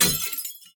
glass4.ogg